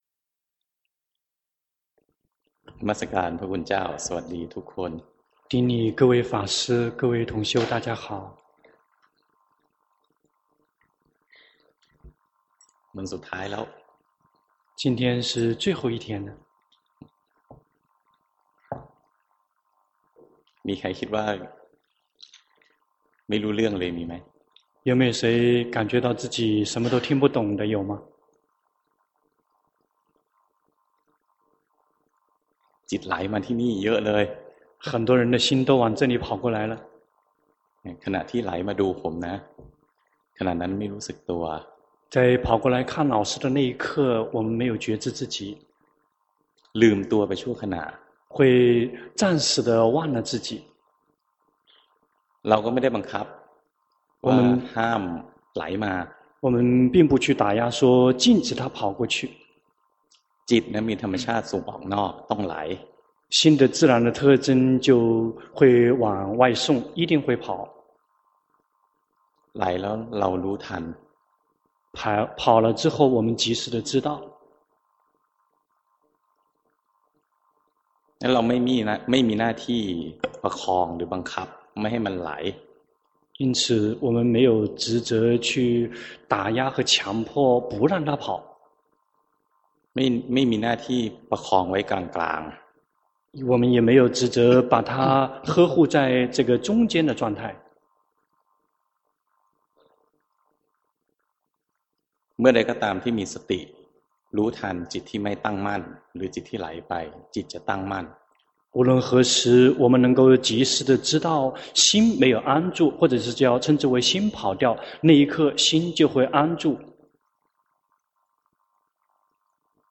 長篇法談｜你所不了解的「四念處」